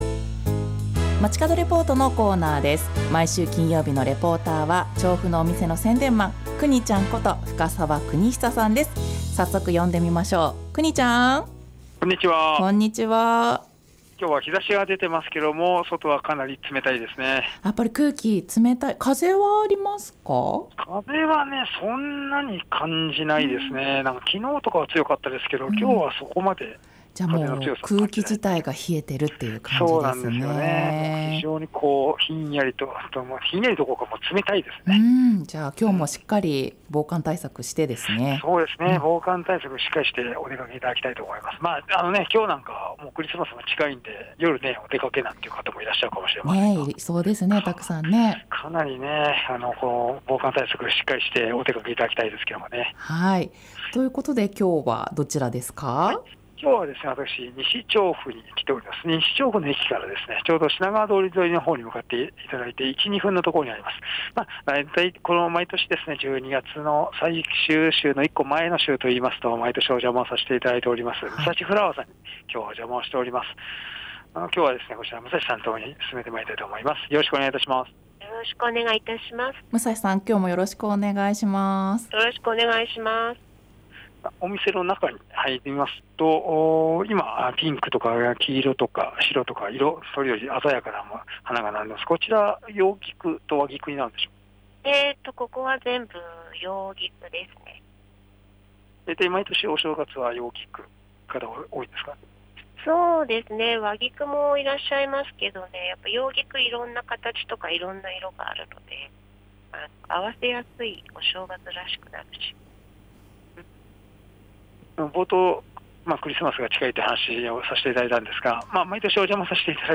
本日は西調布駅から品川通り沿い歩いて１・２分にありますムサシフラワーさんからお届けしました。